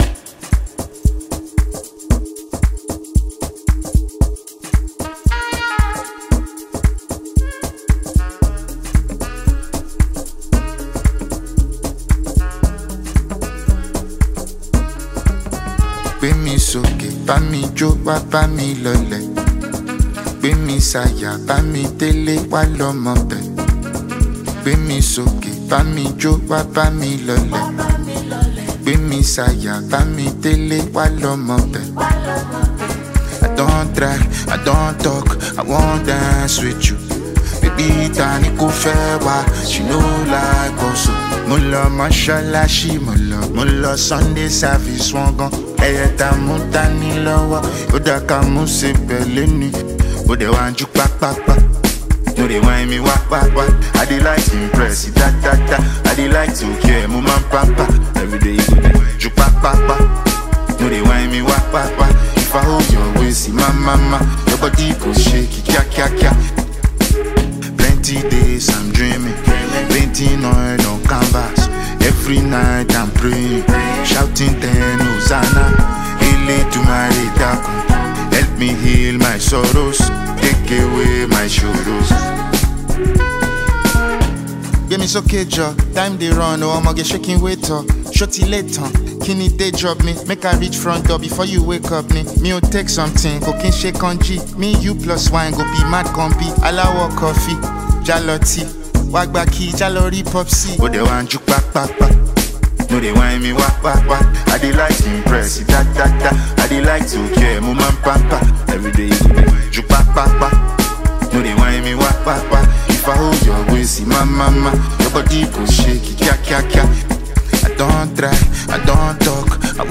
Nigerian rapper and songwriter